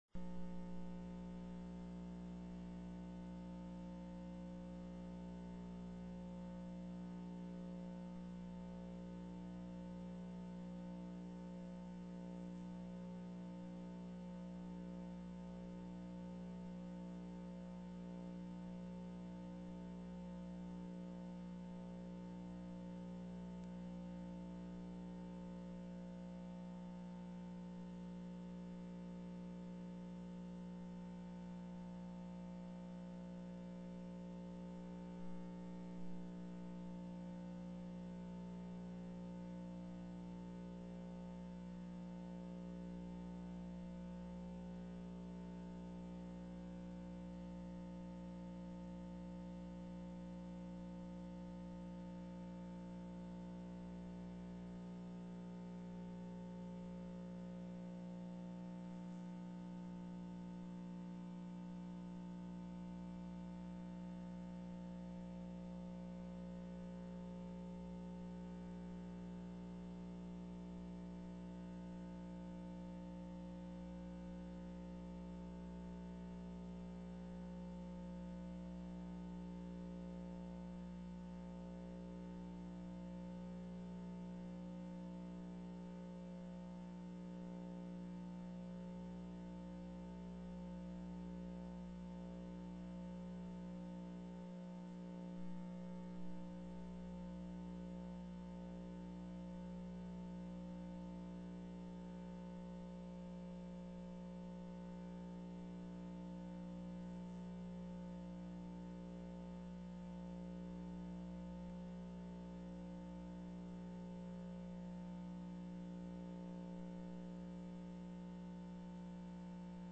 03/06/2006 12:30 PM House FINANCE